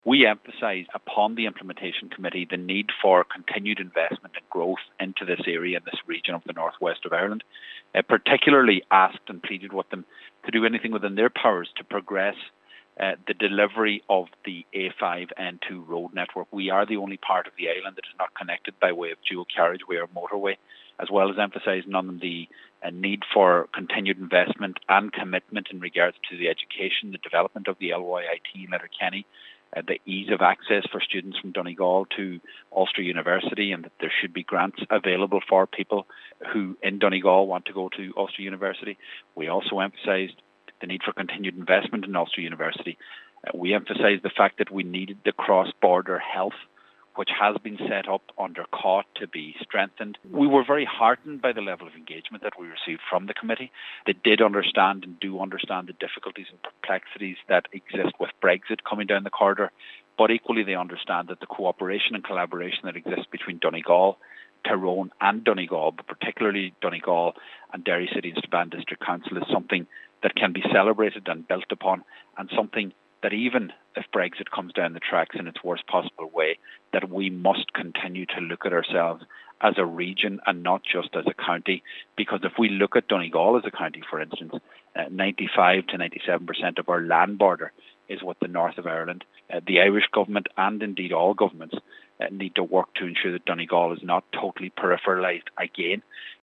Cathaoirleach of Donegal County Council Councillor Seamus O’Domhnail says that in the face of Brexit, it is vital that Donegal is not peripheralised…..